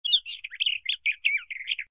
SZ_DG_bird_03.ogg